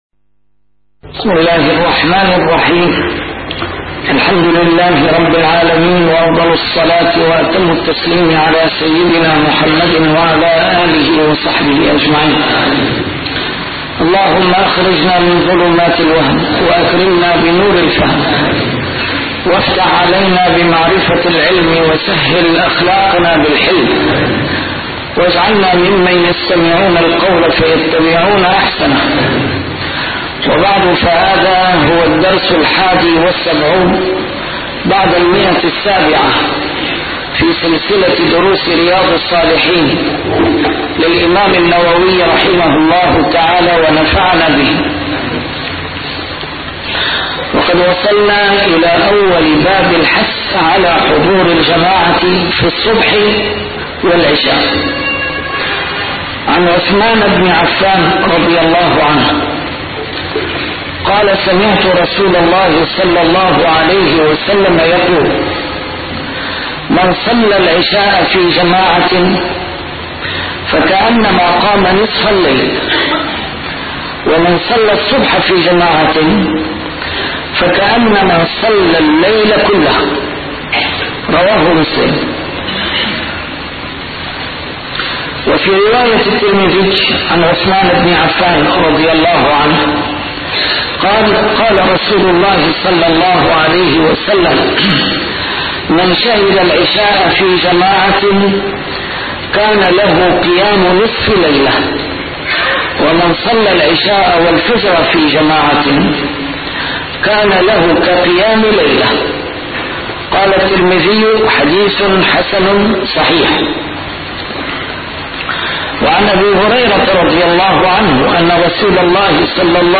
A MARTYR SCHOLAR: IMAM MUHAMMAD SAEED RAMADAN AL-BOUTI - الدروس العلمية - شرح كتاب رياض الصالحين - 771- شرح رياض الصالحين: حضور الجماعة في الصبح والعشاء